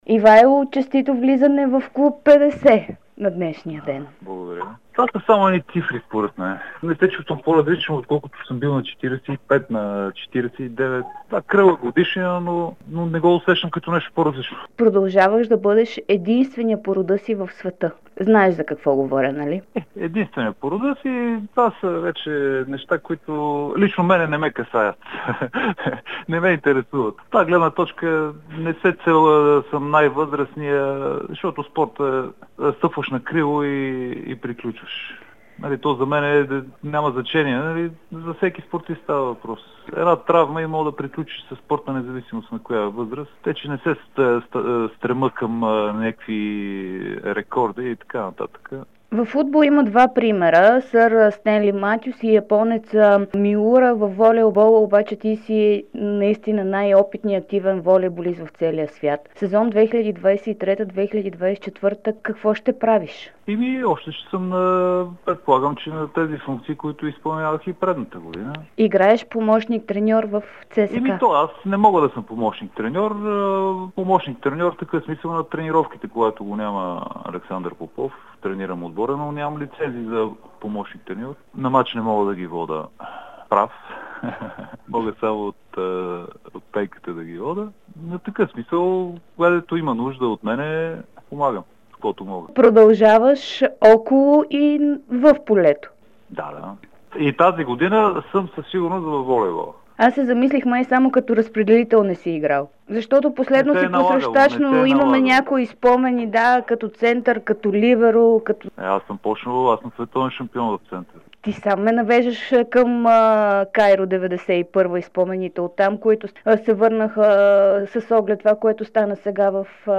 Световният шампион по волейбол за младежи от Кайро през 1991 година даде специално интервю за Дарик и dsport, в което обяви, че остава в полето и през новия сезон 2023/24.